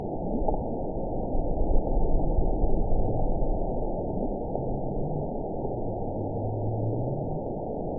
event 918881 date 12/18/23 time 19:48:34 GMT (1 year, 4 months ago) score 8.50 location TSS-AB05 detected by nrw target species NRW annotations +NRW Spectrogram: Frequency (kHz) vs. Time (s) audio not available .wav